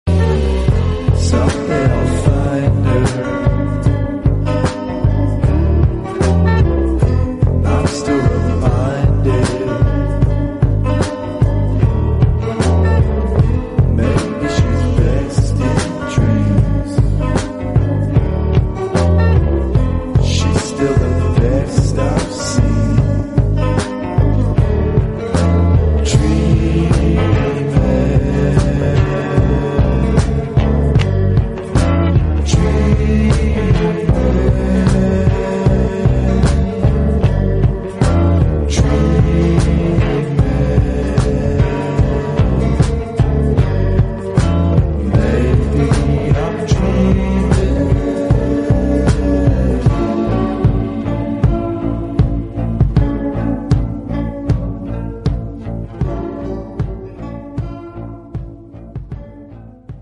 but giving it a chill, almost sleepy groove.
It’s love felt quietly, in slow motion.